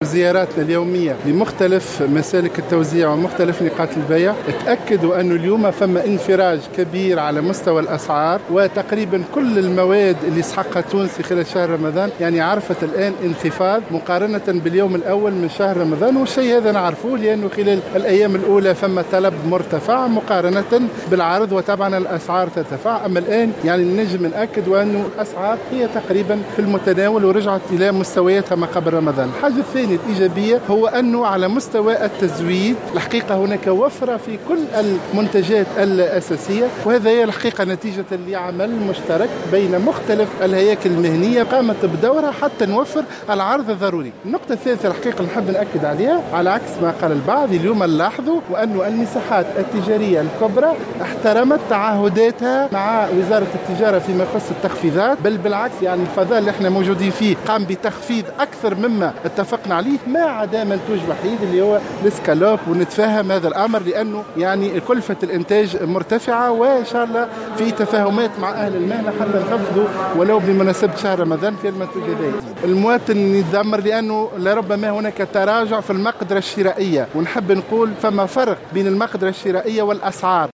أكد وزير التجارة محسن حسن، اليوم الأحد انفراج الأسعار على هامش زيارة أداها اليوم لإحدى المساحات الكبرى في تونس (جيان).